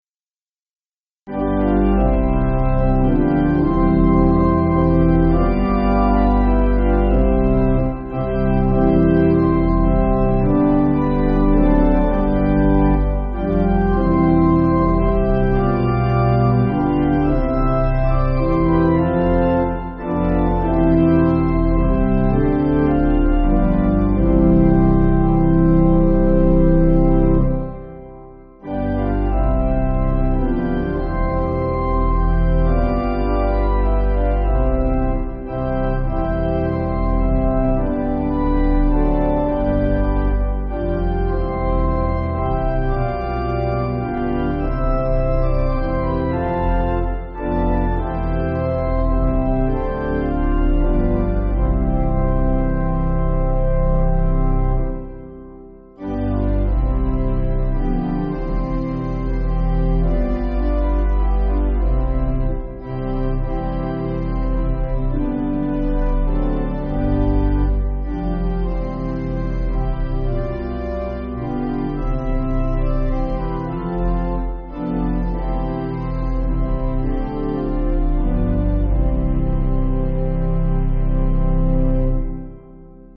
Organ